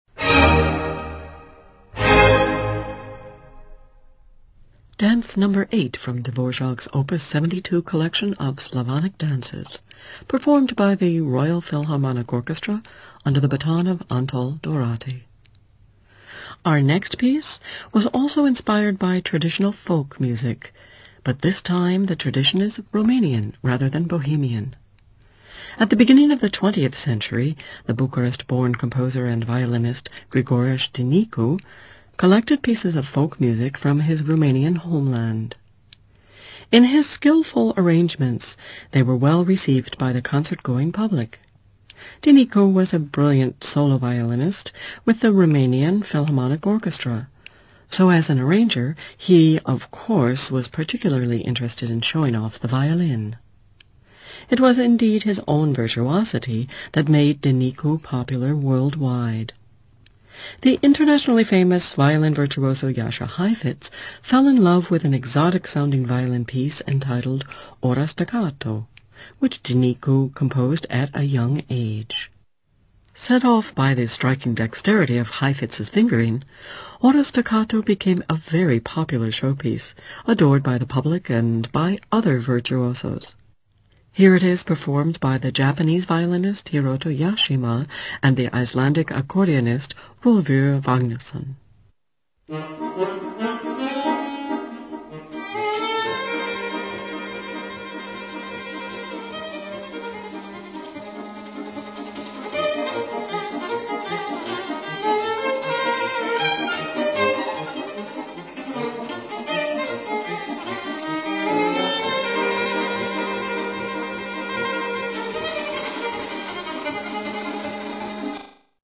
Bei einigen Rapporten habe ich kurze Empfangsdemos als mp3PRO erstellt. So kann man sich ein genaueres Bild über die Klangqualität machen.